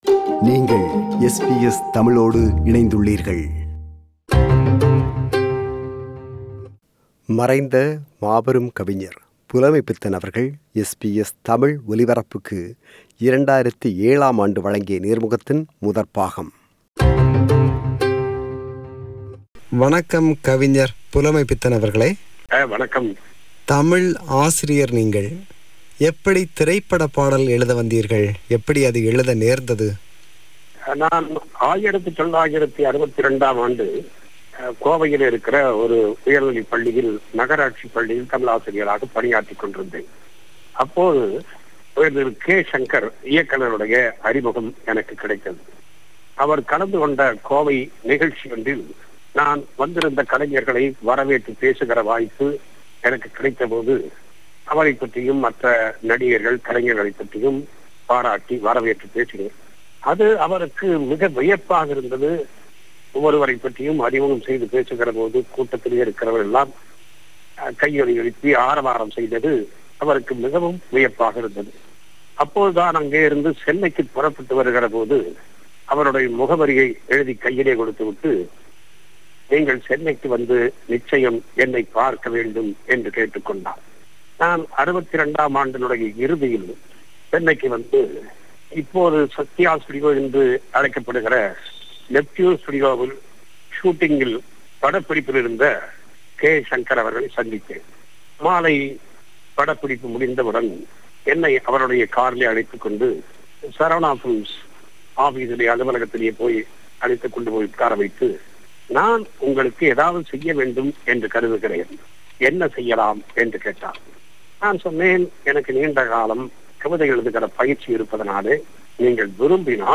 Interview with Poet Pulamaipithan – Part 1